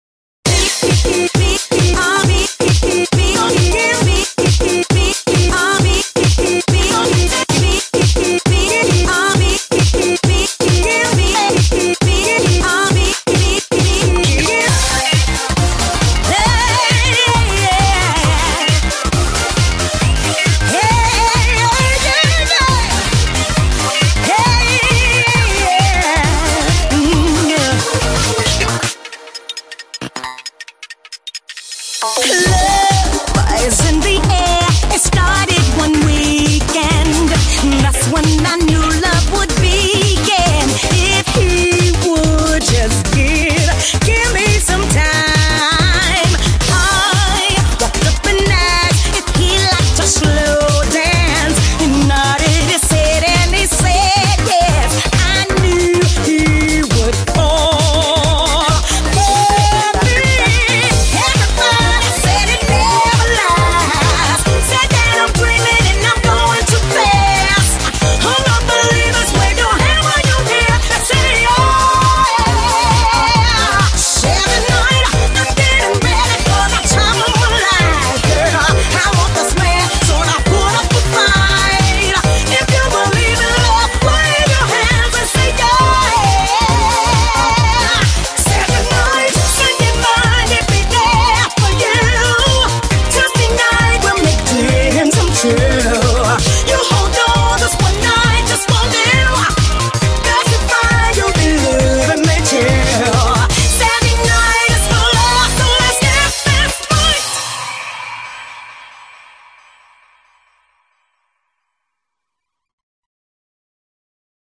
BPM135
Audio QualityPerfect (Low Quality)